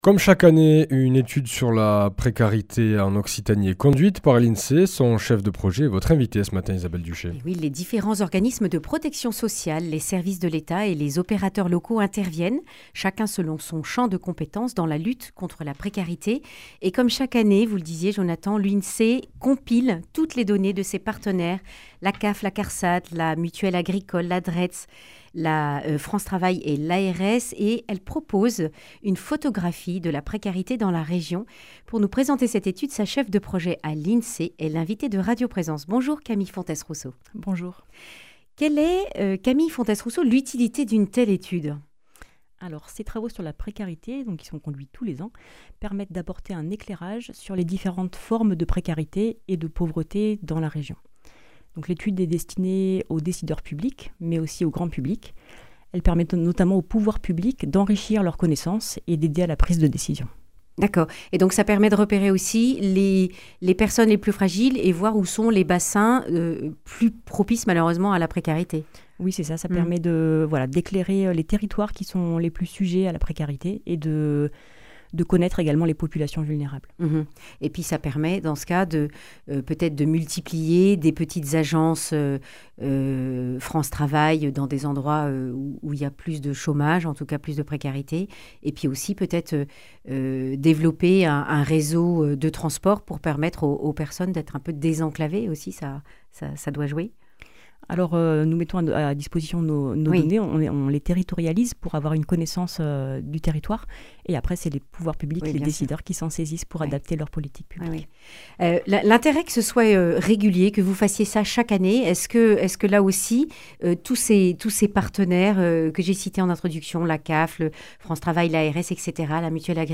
Accueil \ Emissions \ Information \ Régionale \ Le grand entretien \ Quel état de la précarité en Occitanie ?